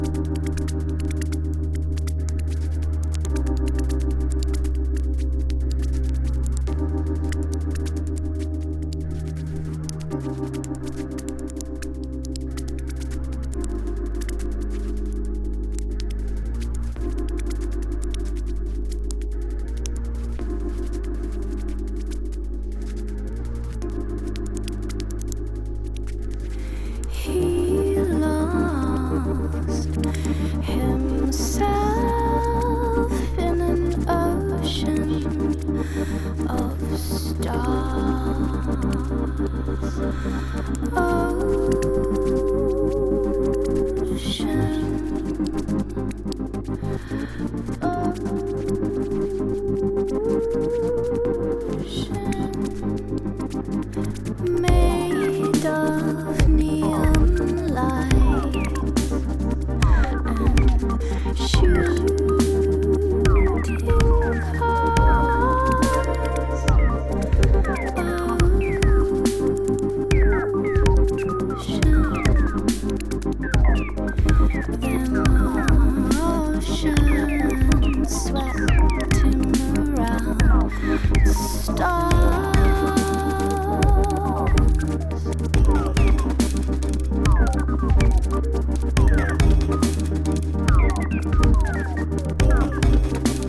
美メロElectronica Bass名曲。